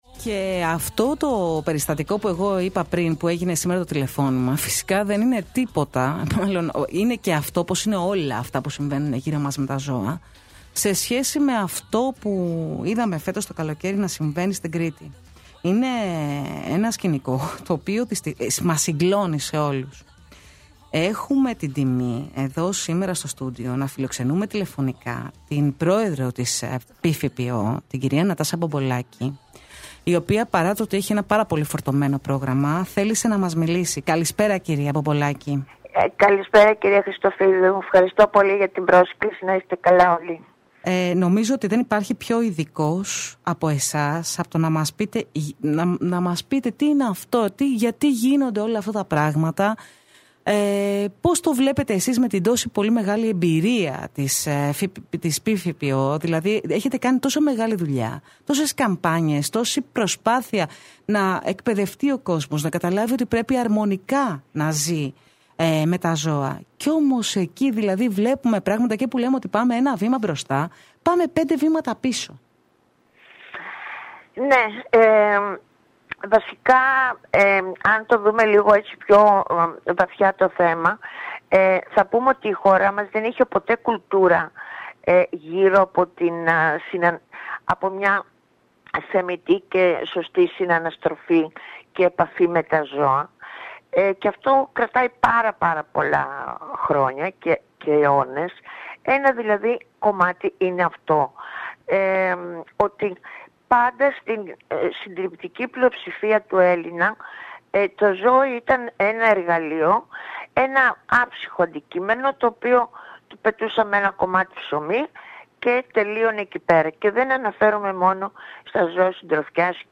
Συνέντευξη
στον ραδιοφωνικό σταθμό 94 στα FM στην εκπομπή Μαύρη Γάτα